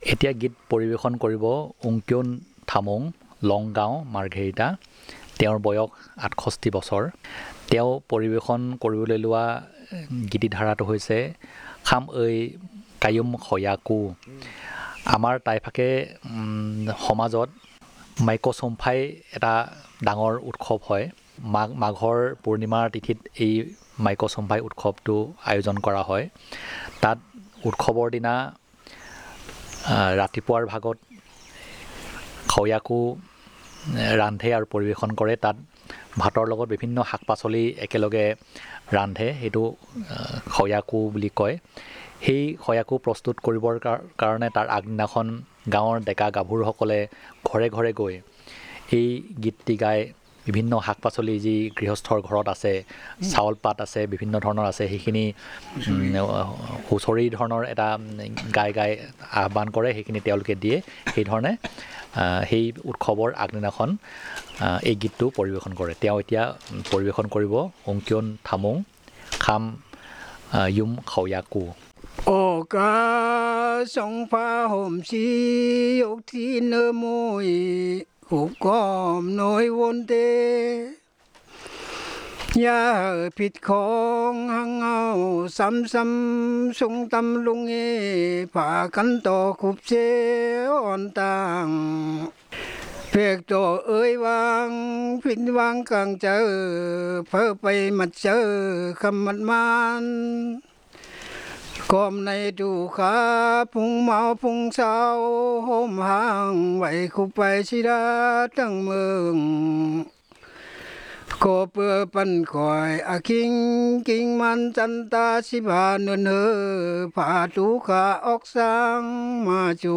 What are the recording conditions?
Performance of a festival song